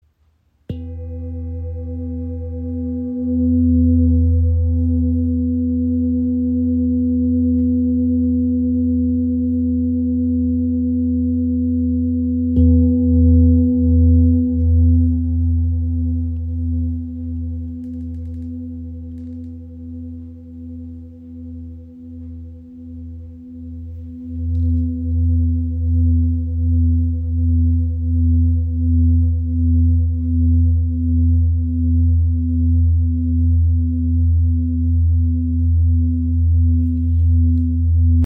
Klangschale mit Yin Yang Baum | ø 30 cm | Ton ~ E2 | Sonnenton (256 Hz)
• Icon Inklusive passendem rotem Filzschlägel.
• Icon Grundton E2 bei 251 Hz – nahe am kraftvollen Sonnenton
Diese handgefertigte Klangschale aus Nepal trägt im Inneren ein fein gestaltetes Yin Yang Symbol in Form eines Baumes – ein kraftvolles Zeichen für Balance, Verwurzelung und inneres Wachstum. Ihr obertonreicher, warmer Klang lädt Dich ein, zur Ruhe zu kommen und Dich mit Deiner Mitte zu verbinden.